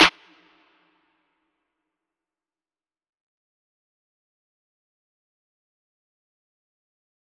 DMV3_Clap 15.wav